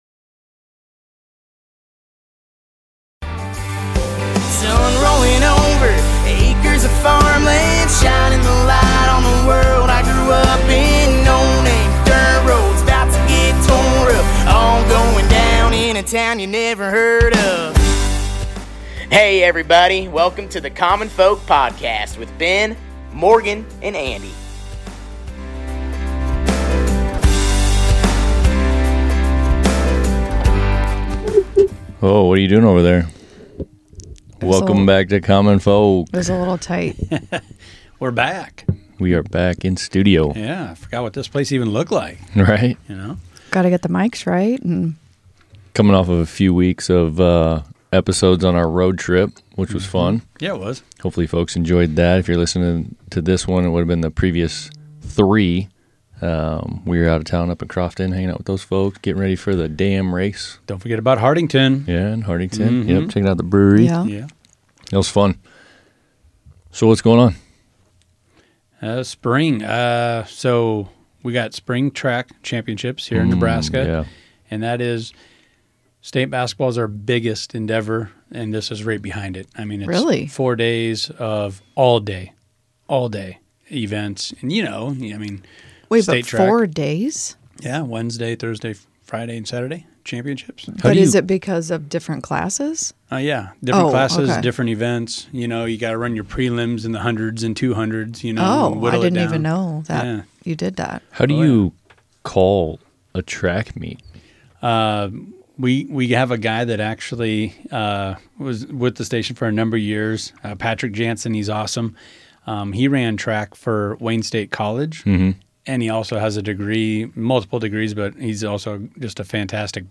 In this episode of Common Folk, we take you on-location to a small-town microbrewery where passion meets perseverance. Join us as we sit down with a local brewer who's betting on his dream, diving headfirst into the craft beer scene—a niche some say has lost its novelty. From the clink of glasses to the hum of fermenters, experience the sights and sounds of a brewery that's more than just a business; it's a labor of love.